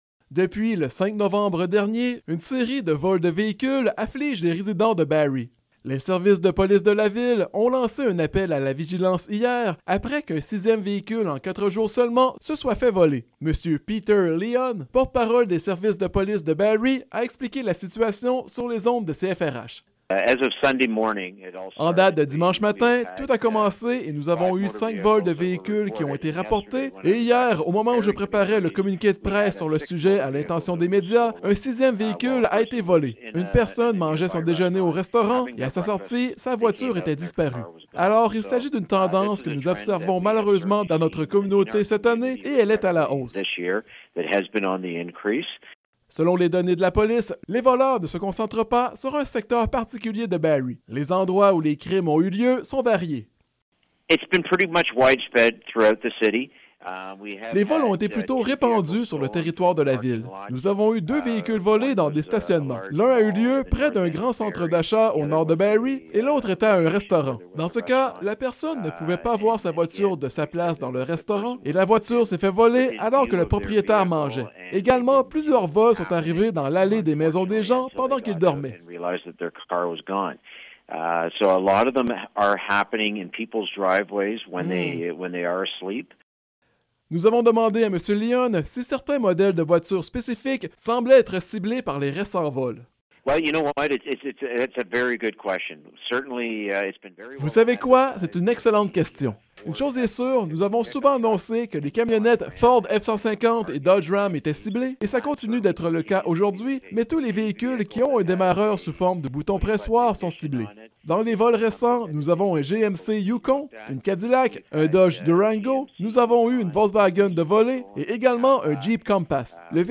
Reportage-vols-de-voitures.mp3